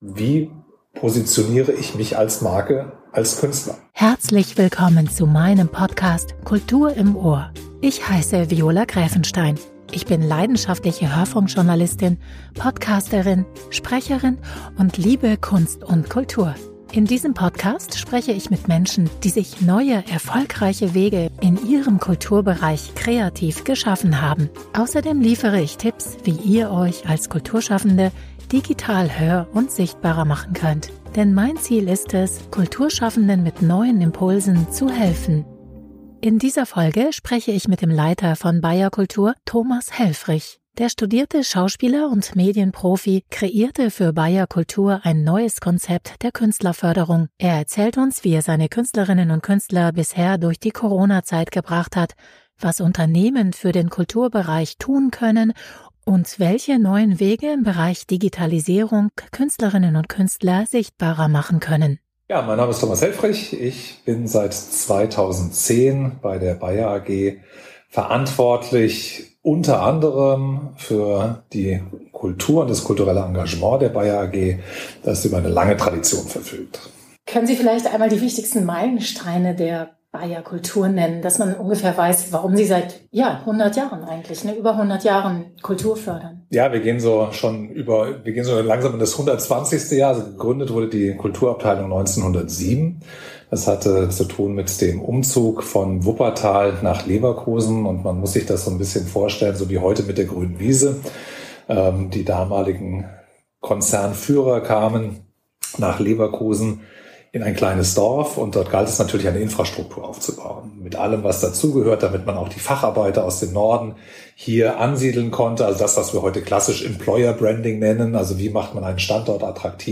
Dazu kommen Menschen sowie Expert*innen aus der Kunst und Kultur zu Wort, die ganz eigene Wege gehen und etwas zum Thema erfolgreiche Selbstvermarktung und Kreativität beitragen können.